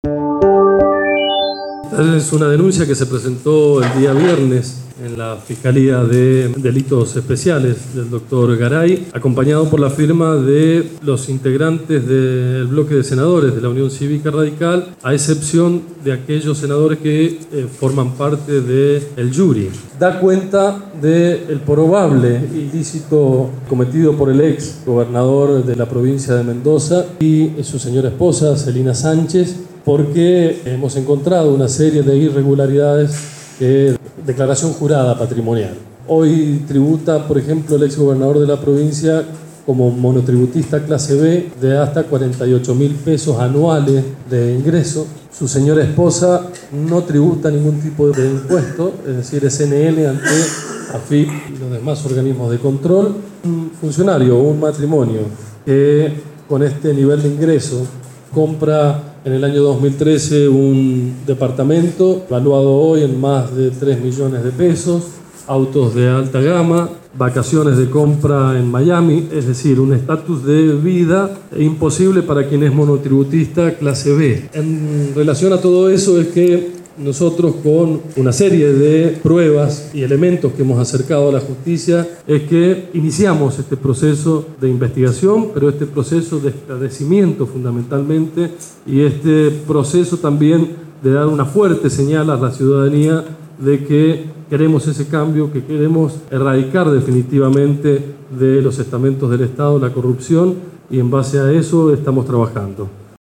Audio senador Alejandro Molero